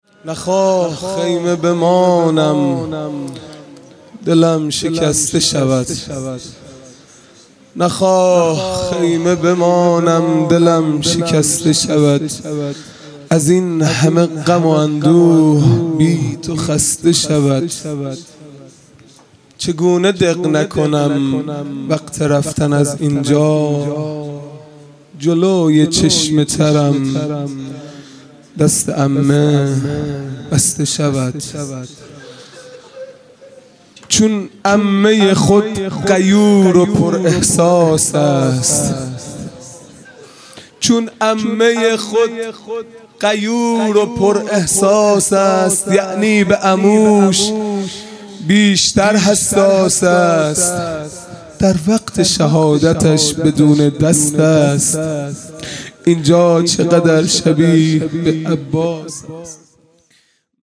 خیمه گاه - هیئت بچه های فاطمه (س) - مرثیه | نخواه خیمه بمانم دلم شکسته شود
محرم ۱۴۴۱ |‌ شب پنجم